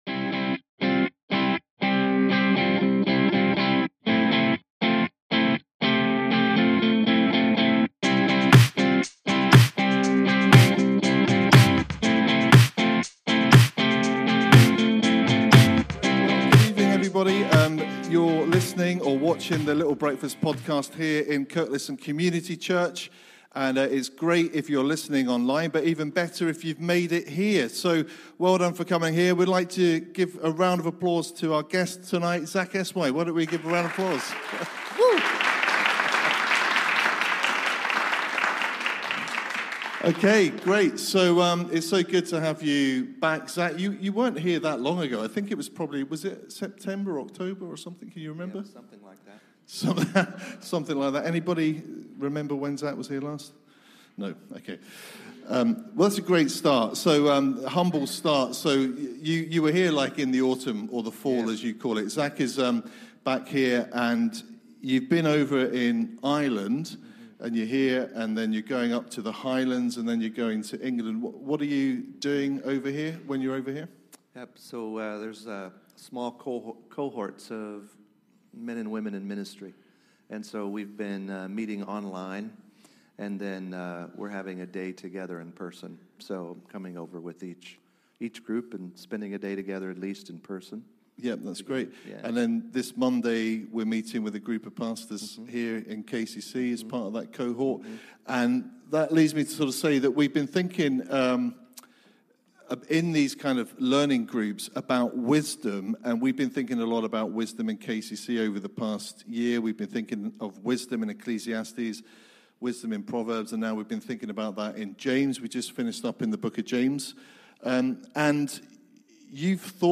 Recorded live at Kirkliston Community Church on 16 March 2025.